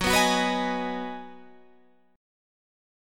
B6/Gb chord